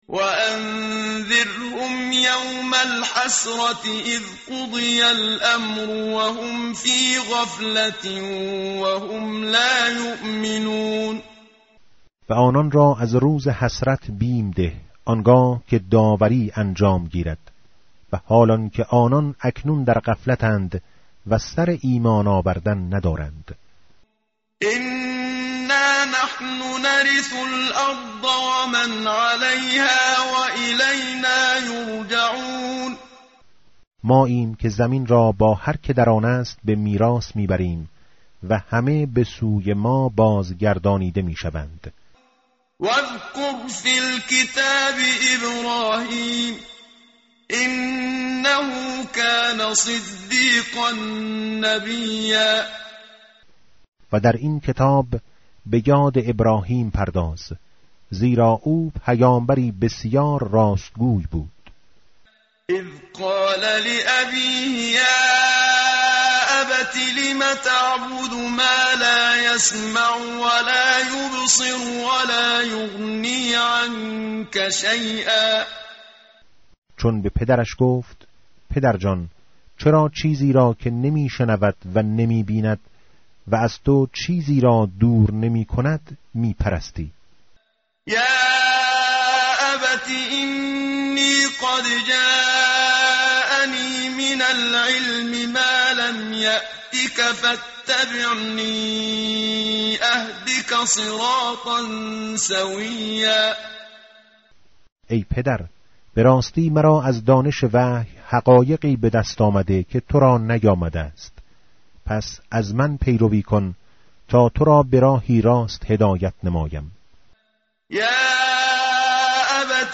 tartil_menshavi va tarjome_Page_308.mp3